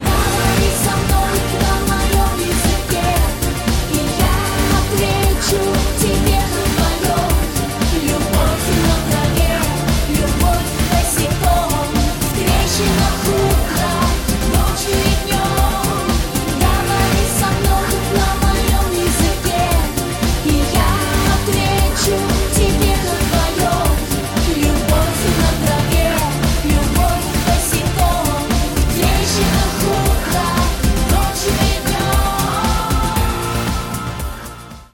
инди